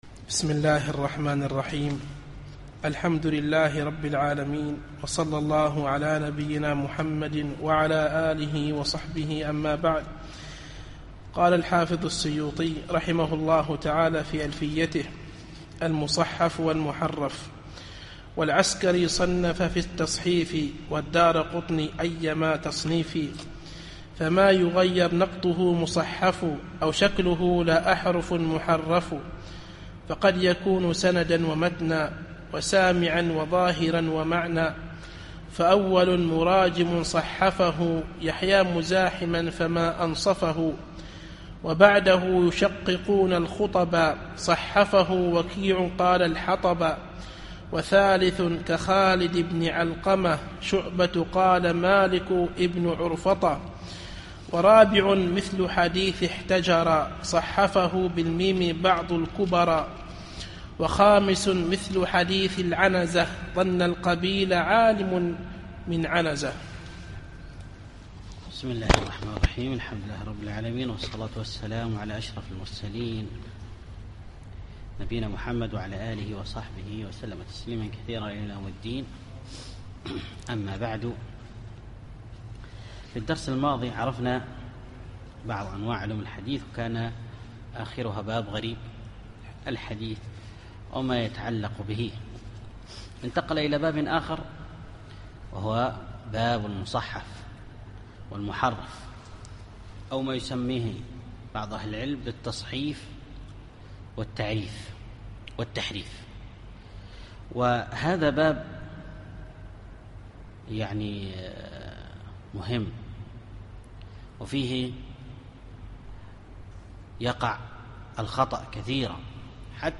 الدرس الثامن والعشرون